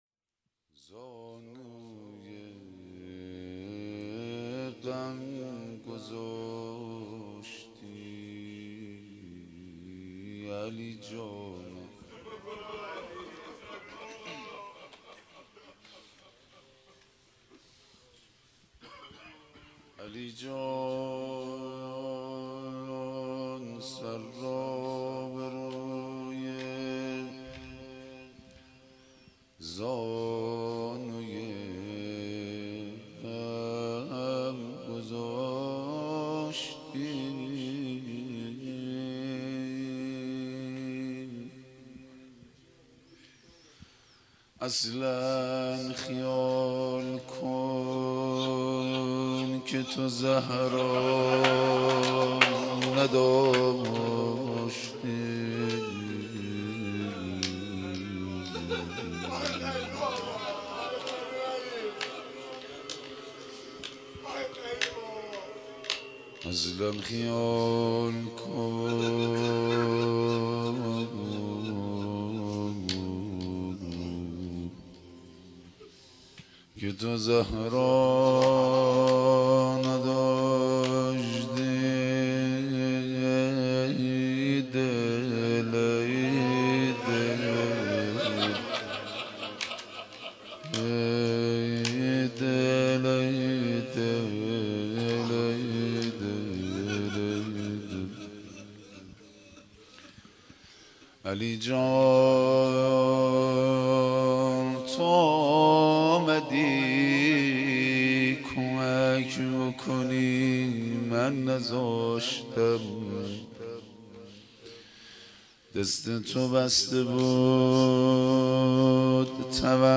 روضه مداحی